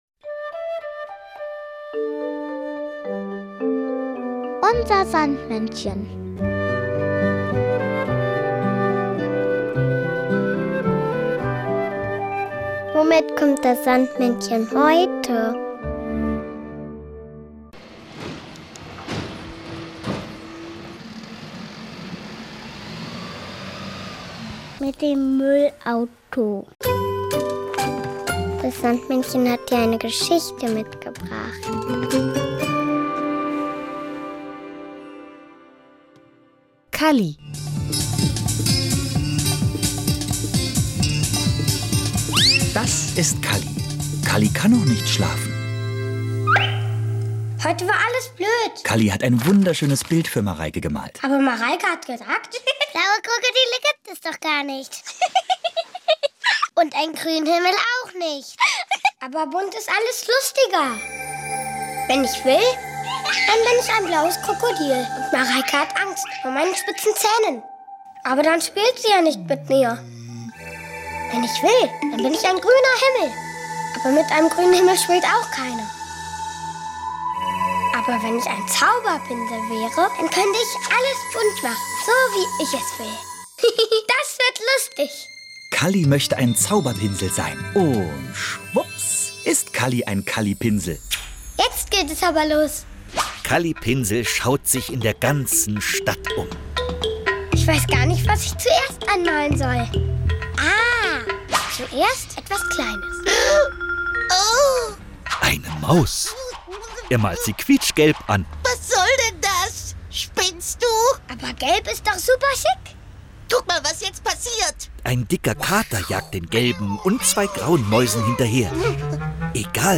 Kinderlied "Schweinchen bunt" von den 17 Hippies.